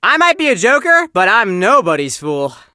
chester_lead_vo_08.ogg